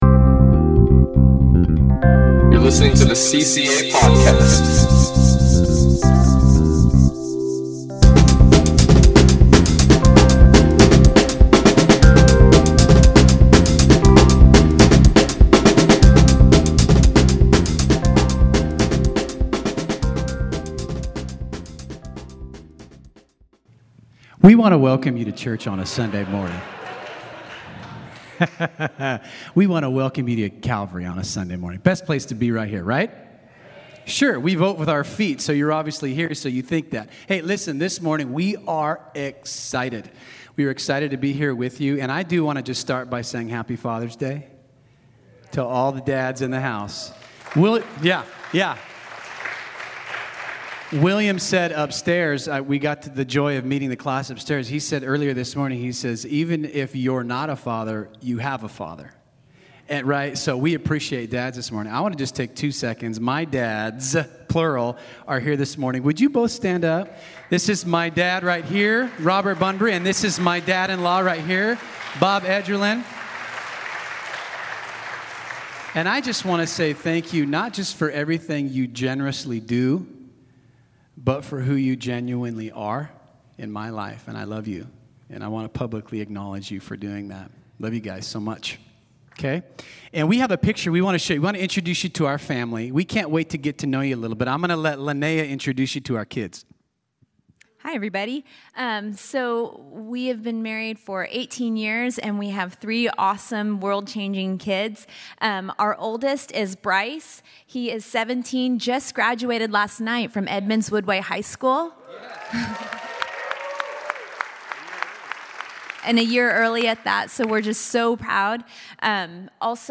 Listen to Message | Download Notes